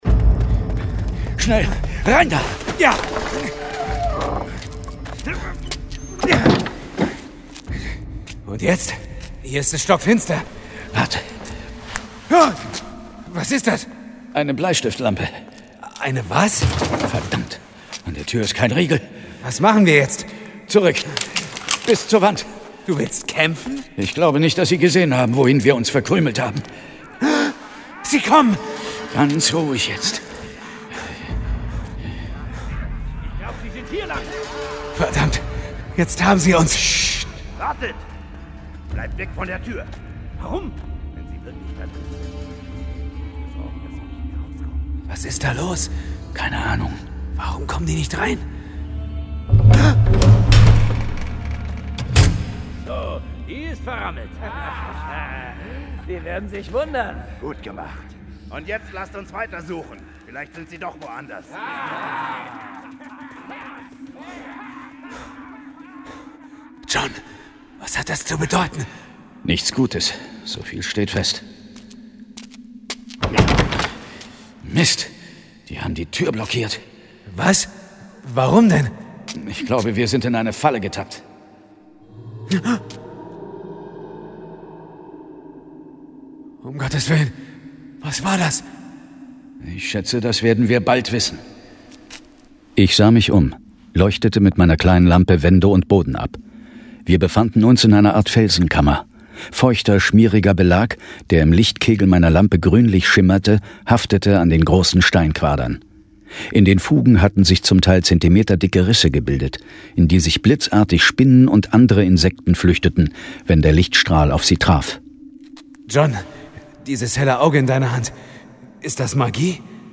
John Sinclair - Folge 39 Schreie in der Horror-Gruft. Hörspiel.